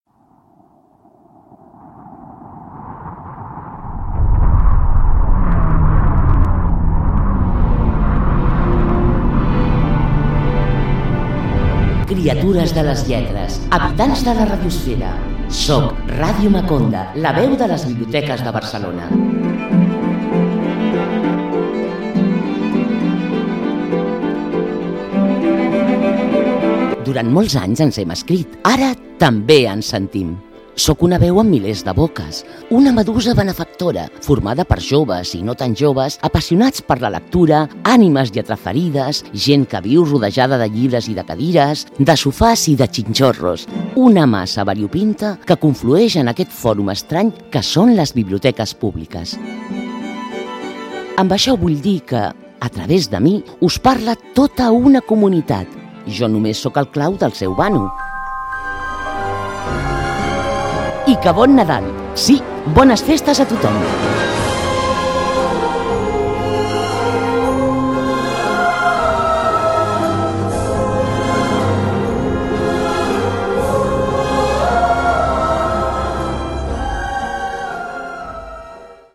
Gran presentació còsmica i nadalenca de la veu de les Biblioteques de Barcelona, en la primera temporada de Ràdio Maconda.